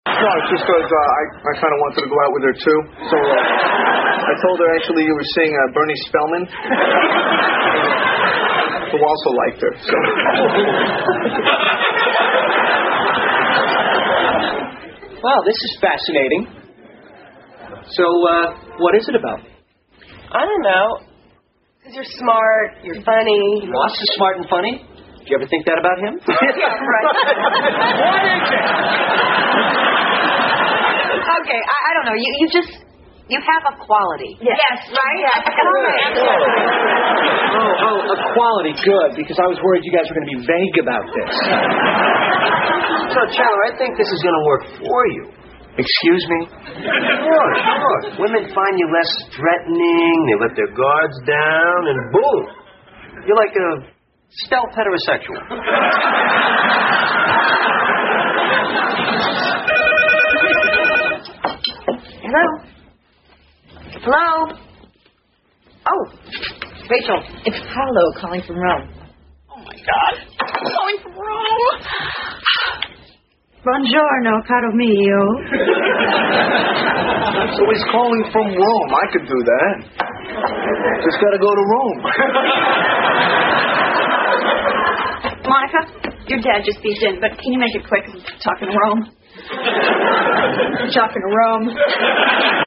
在线英语听力室老友记精校版第1季 第88期:祖母死了两回(2)的听力文件下载, 《老友记精校版》是美国乃至全世界最受欢迎的情景喜剧，一共拍摄了10季，以其幽默的对白和与现实生活的贴近吸引了无数的观众，精校版栏目搭配高音质音频与同步双语字幕，是练习提升英语听力水平，积累英语知识的好帮手。